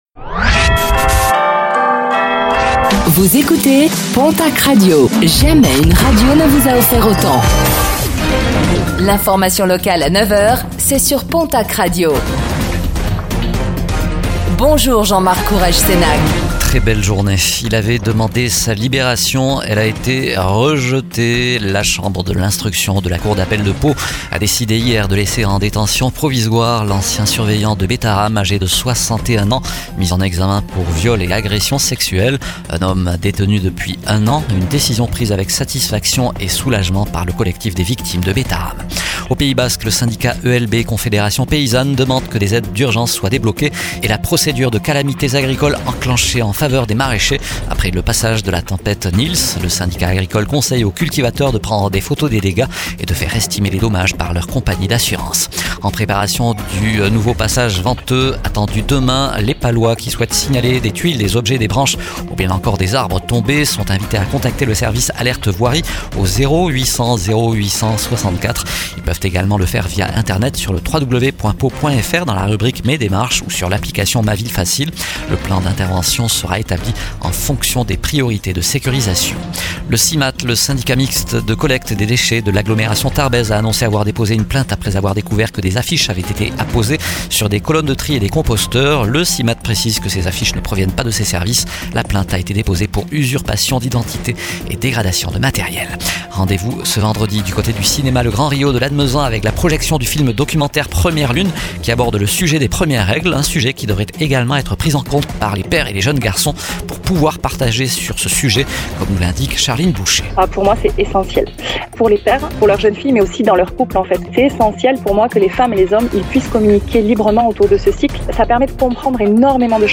09:05 Écouter le podcast Télécharger le podcast Réécoutez le flash d'information locale de ce mercredi 18 février 2026